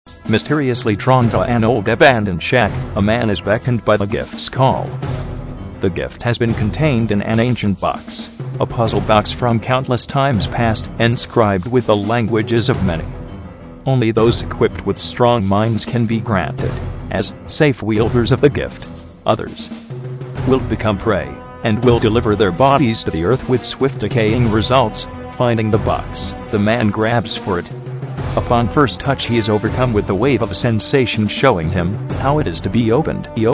I was having too much fun testing AT&T's Natural VoicesText-to-Speech Engine... :p
Anyway, here is part 1 using "Mike's" voice as my narrator...